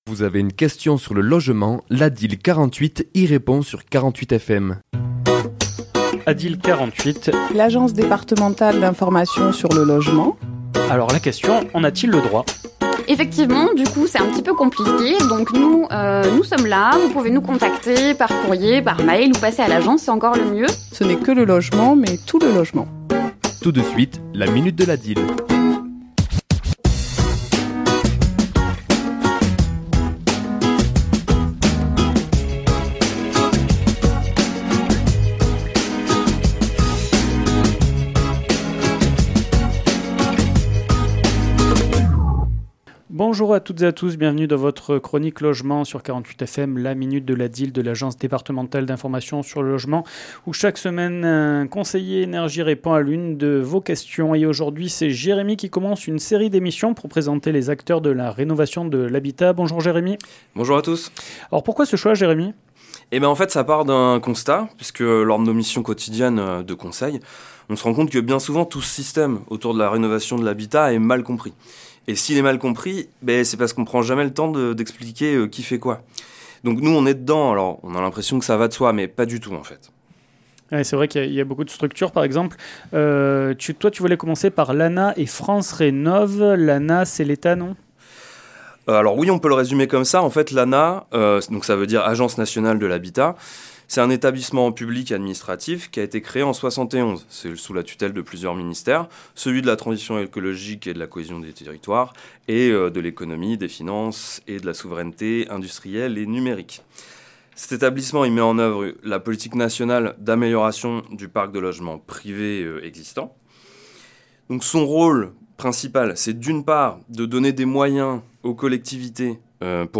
Chronique diffusée le mardi 10 décembre à 11h et 17h10